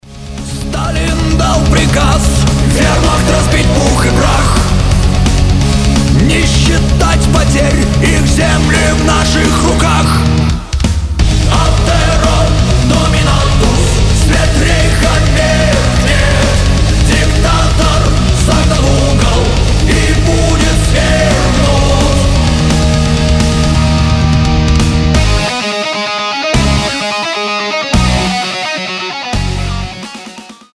рок
метал
power metal